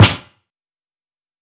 • Damped Snare Drum A Key 53.wav
Royality free acoustic snare sound tuned to the A note. Loudest frequency: 1464Hz
damped-snare-drum-a-key-53-bR6.wav